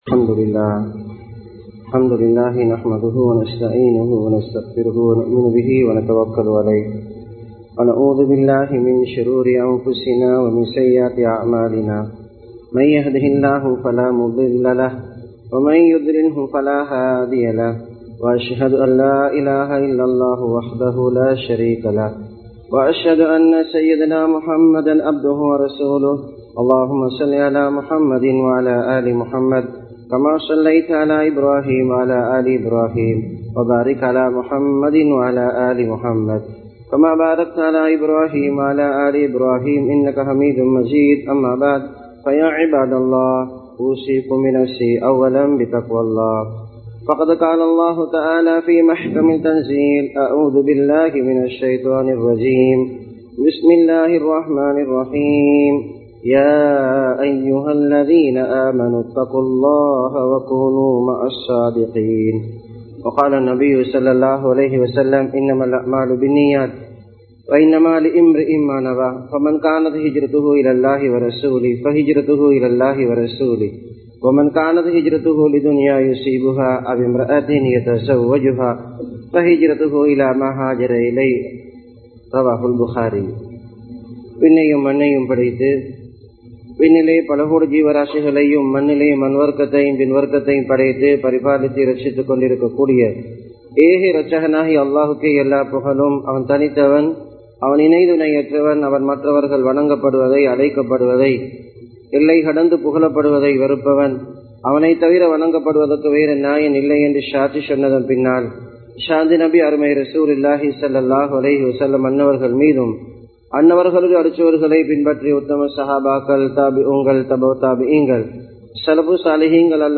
அல்லாஹ்வின் நேசம் (Prettiness of Allah) | Audio Bayans | All Ceylon Muslim Youth Community | Addalaichenai